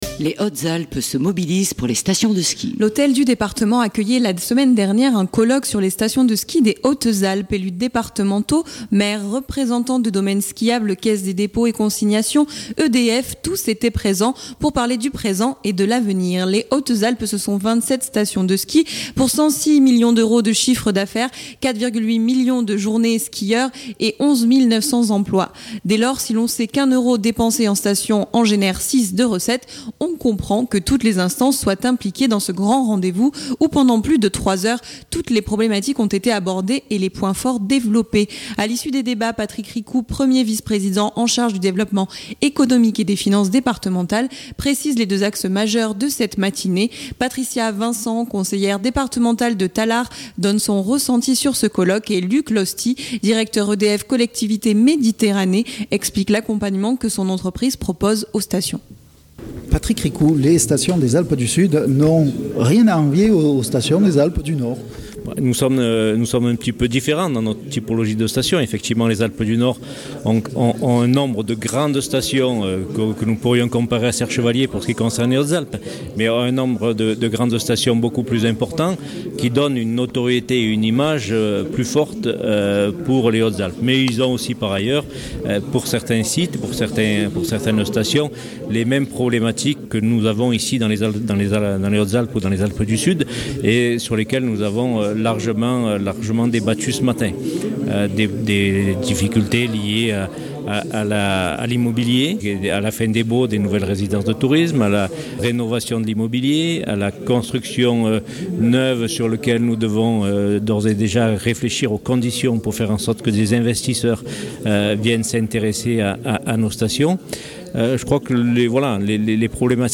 L’hôtel du département accueillait la semaine dernière hier un colloque sur les stations de ski des Hautes-Alpes.